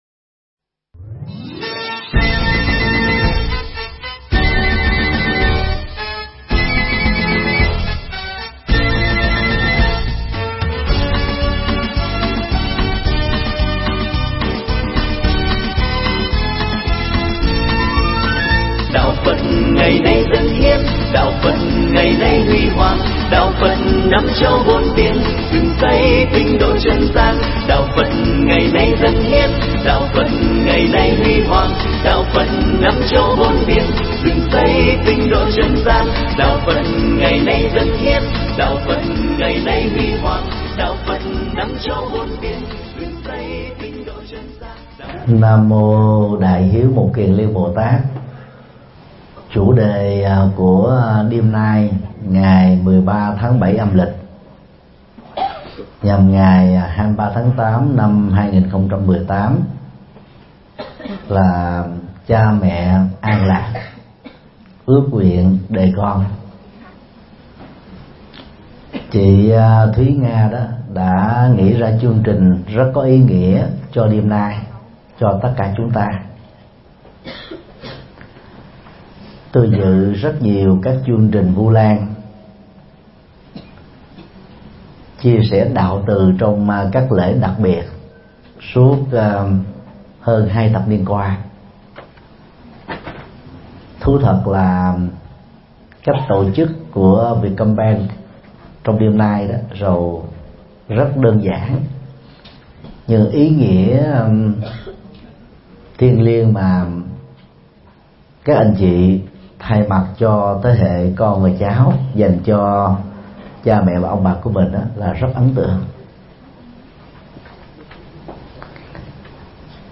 Mp3 Thuyết Giảng Cha Mẹ An Lạc – Ước Nguyện Đời Con – Thượng Tọa Thích Nhật Từ giảng tại Vietcombank, ngày 23 tháng 8 năm 2018